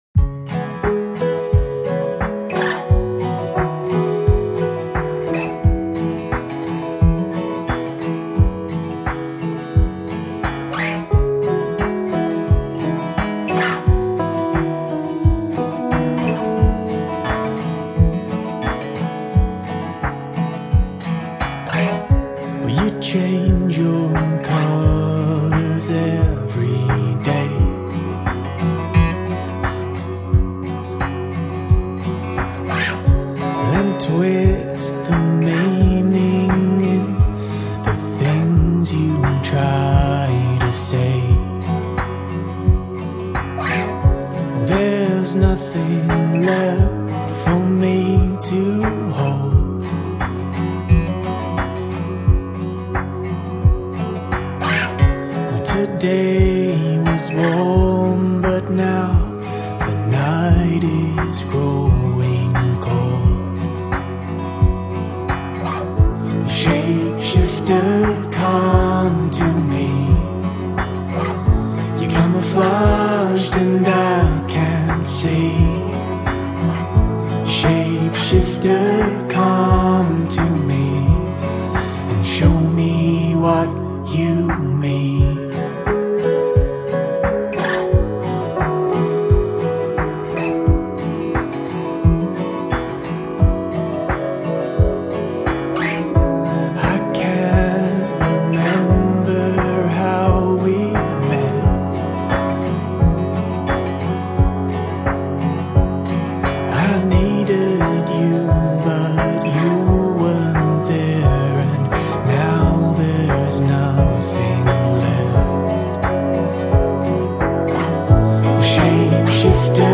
atmospheric indie-folk
Australian singer-songwriter